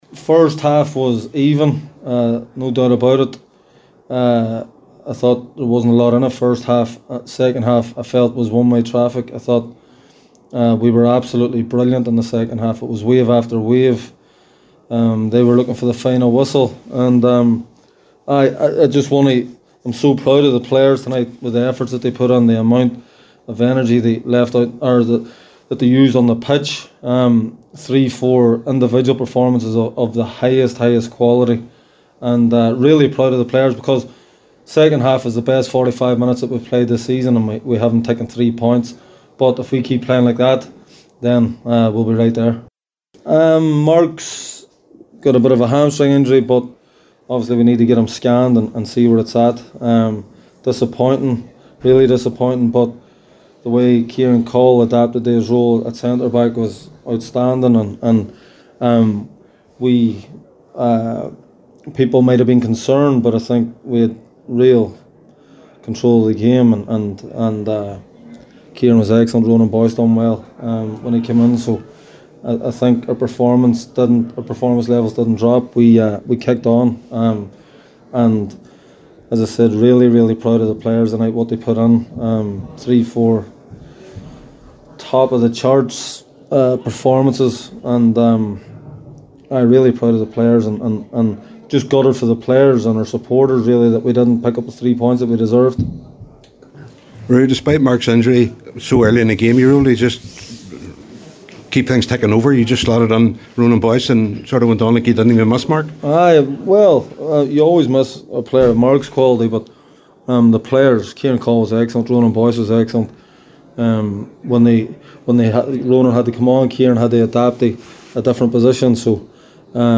Speaking with the media after the game